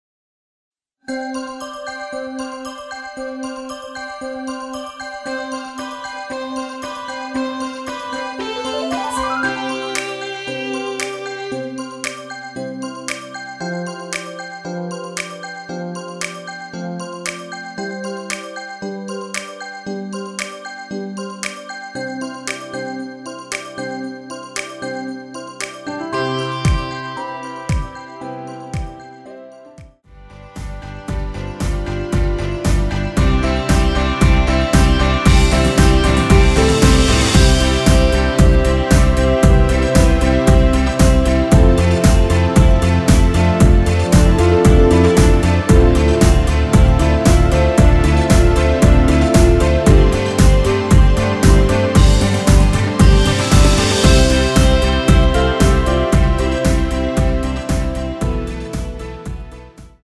내린 MR입니다.
엔딩이 페이드 아웃이라 노래 부르기 좋게 엔딩 만들었습니다.
앞부분30초, 뒷부분30초씩 편집해서 올려 드리고 있습니다.
곡명 옆 (-1)은 반음 내림, (+1)은 반음 올림 입니다.